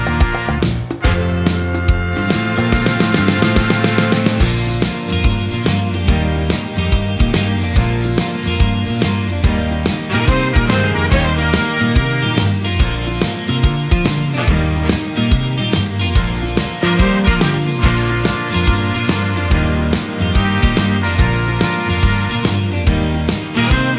Minus Guitar Pop (1990s) 2:39 Buy £1.50